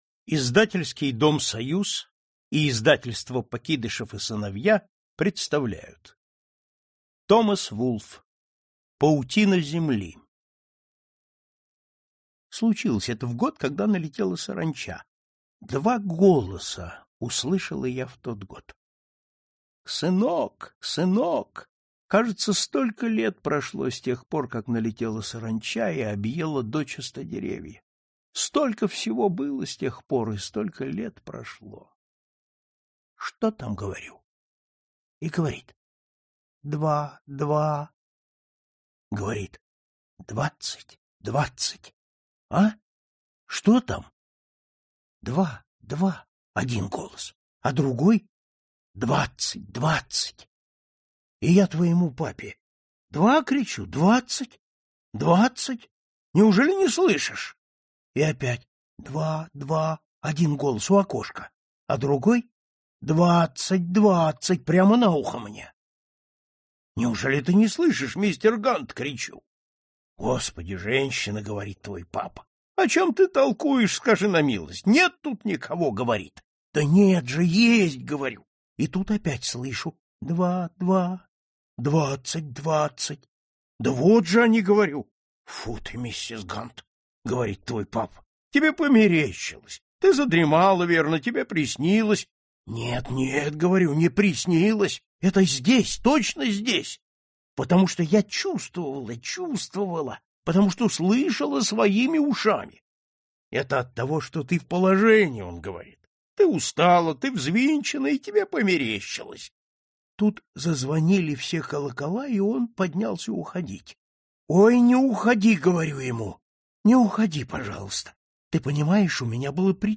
Аудиокнига Паутина земли | Библиотека аудиокниг